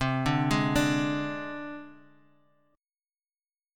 Csus2#5 chord